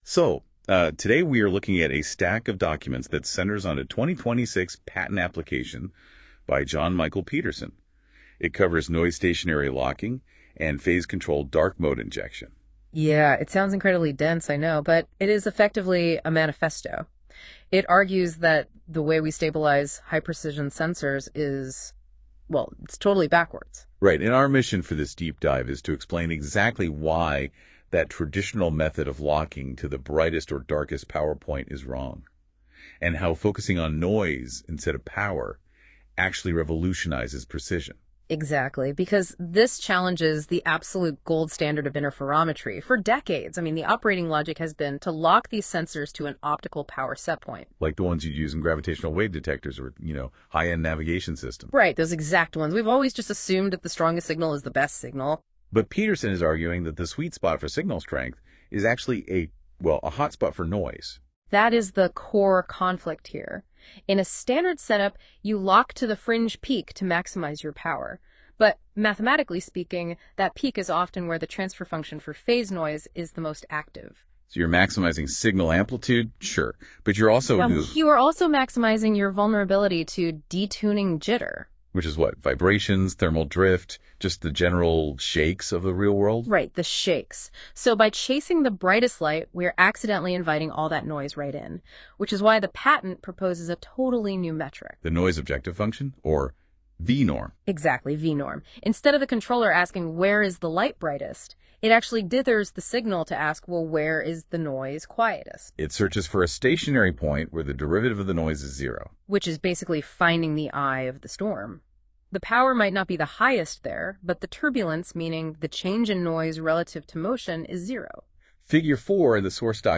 Metrology 101 — spoken walkthrough